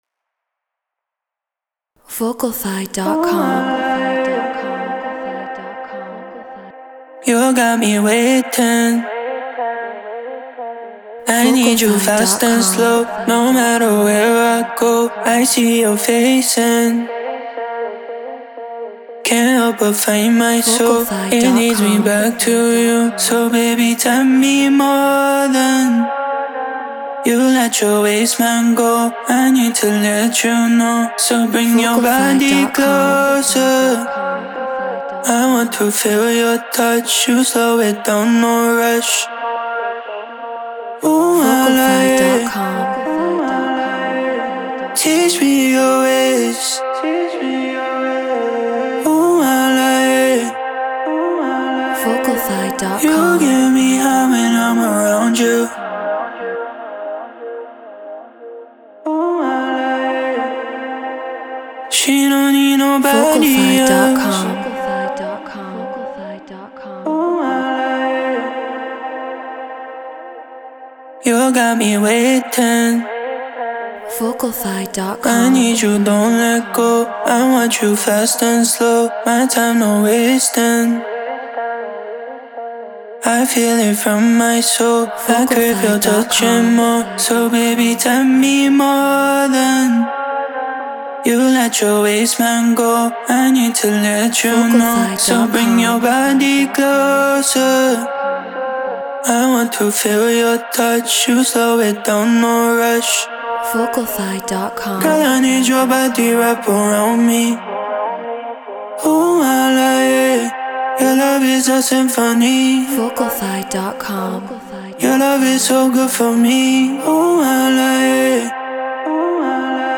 Afro House 120 BPM Fmin
MXL 990 Volt 2 FL Studio Treated Room